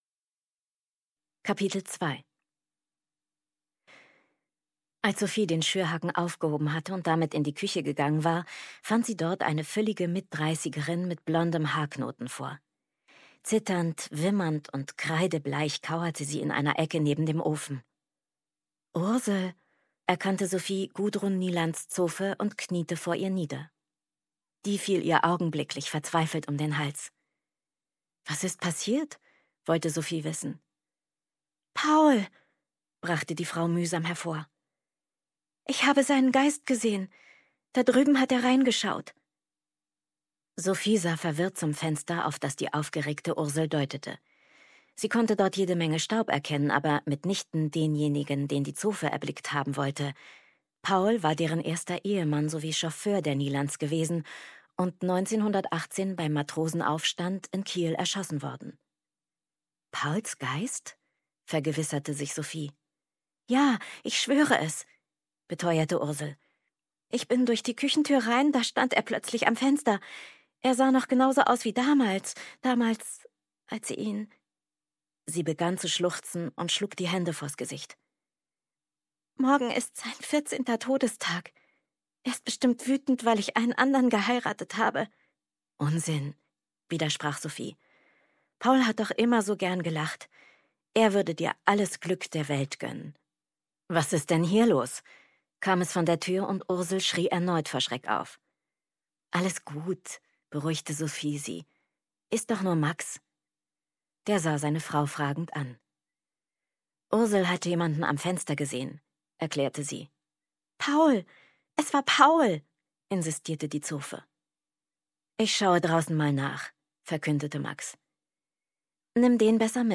2020 | ungekürzte Lesung